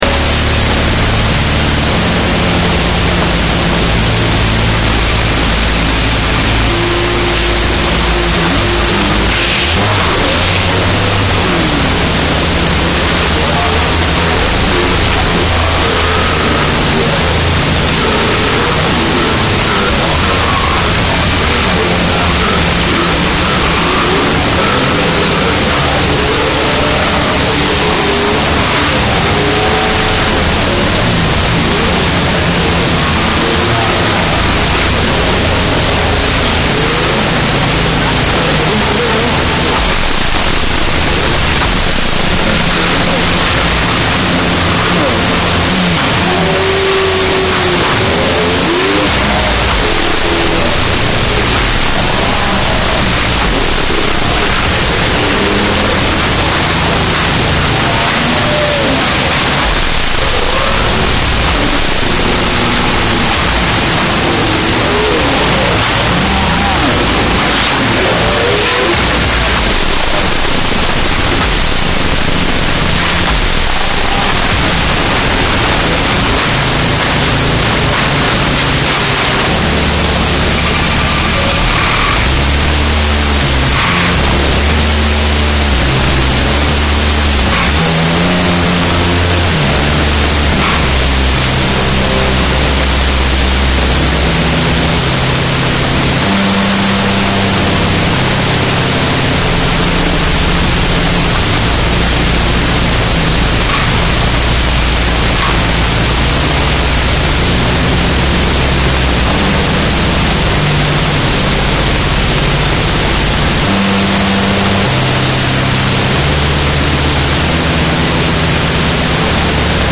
师生演绎十首
优美原创歌曲